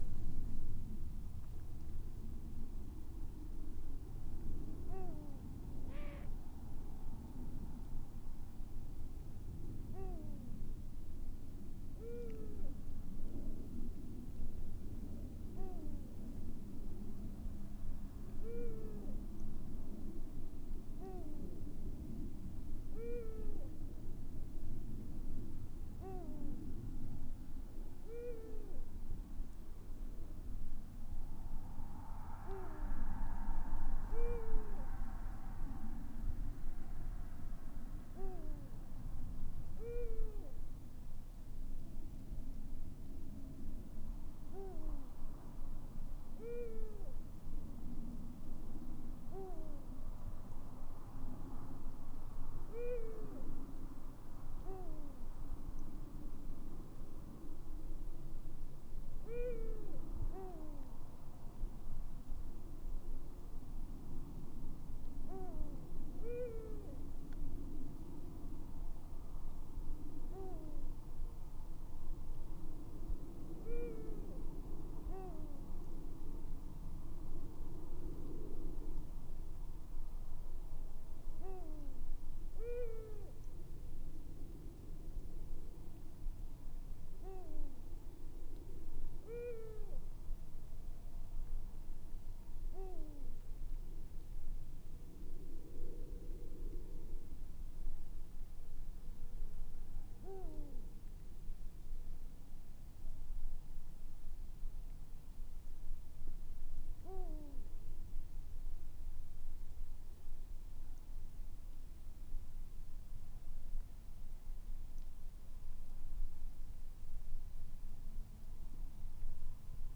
bubo-bubo
Basé sur l’enregistrement des chants des adultes il devrait permettre de reconnaître les hiboux entre eux grâce à une signature vocale de chaque individu. Pour l’instant les hiboux ont été enregistrés sur 5 sites, ces sites seront suivis les prochaines années et peut-être découvririons nous des us et coutumes mystérieuses des Bubo…Vous pouvez vous délecter d’un de ces chants mélodieux en cliquant sur le lien ci-dessous (mettez le son à fond!).
chantgrandduclorraine.wav